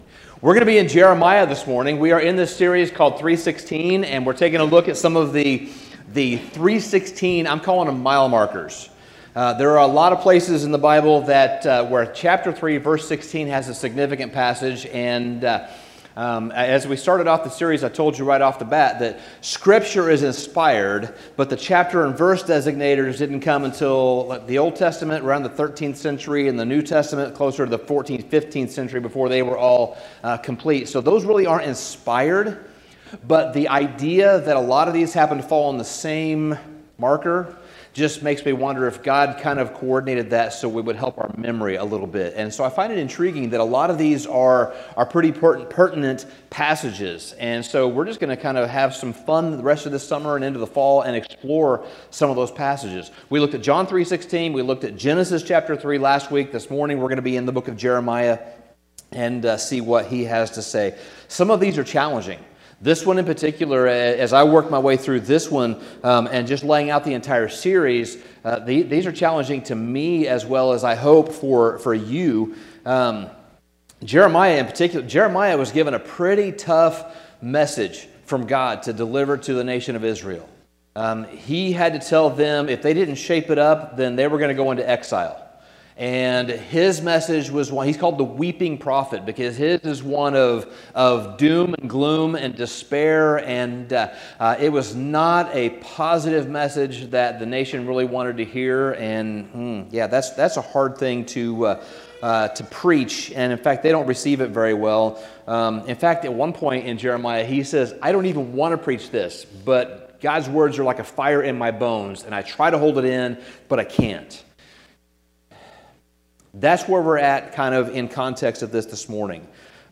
Sermon Summary The book of Jeremiah is filled with gloomy prophecies about the future of Israel.